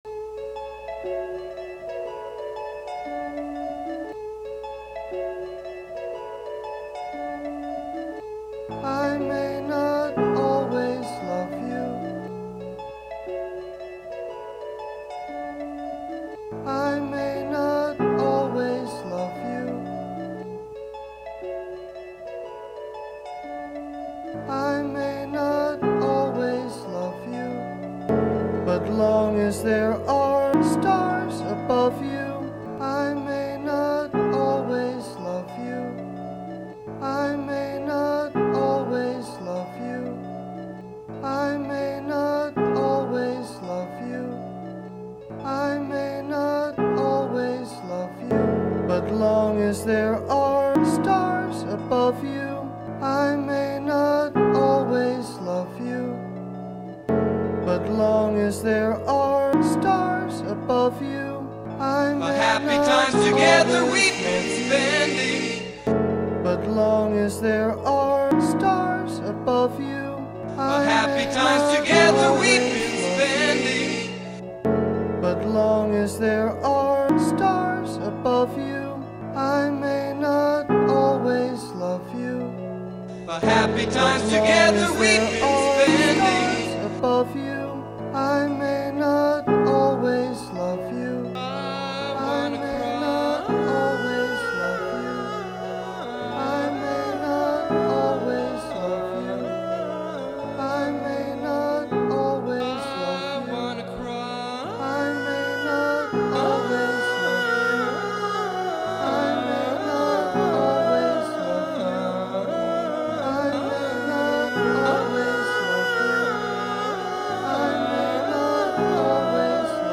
angsty break-up song